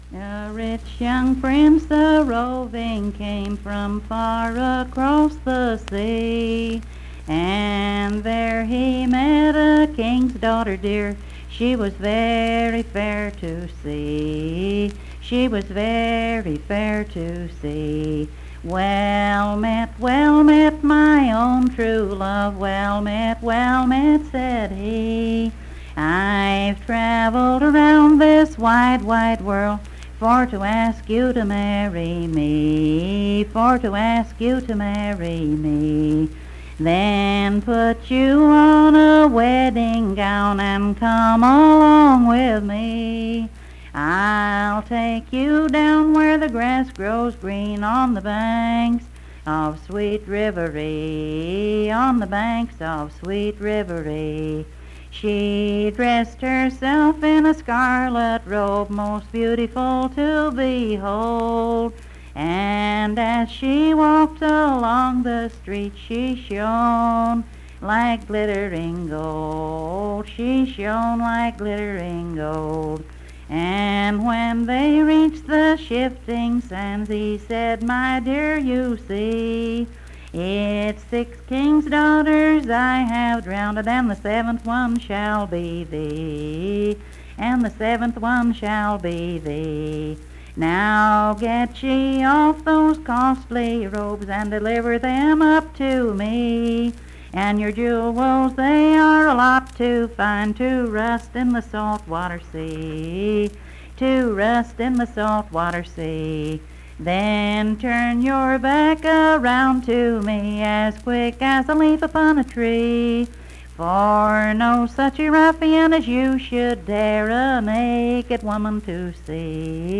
Unaccompanied vocal music
Verse-refrain 9(5w/R).
Performed in Coalfax, Marion County, WV.
Voice (sung)